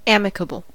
amicable: Wikimedia Commons US English Pronunciations
En-us-amicable.WAV